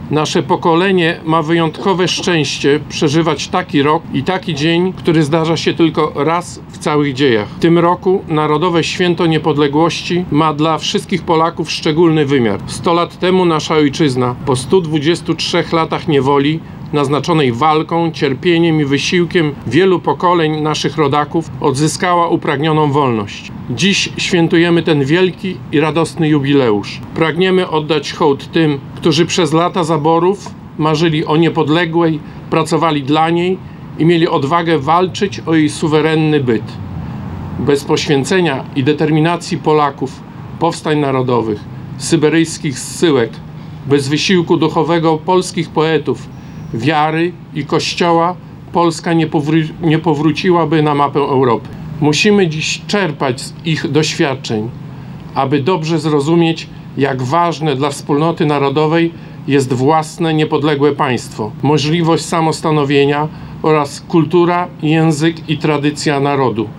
W piątek (09.11) w Parku Konstytucji 3 Maja odbył się uroczysty apel pamięci. Było wspólne śpiewanie pieśni patriotycznych, salwa honorowa i złożenie kwiatów pod Dębem Wolności.
List w jego imieniu przeczytał Grzegorz Mackiewicz, przewodniczący rady powiatu suwalskiego.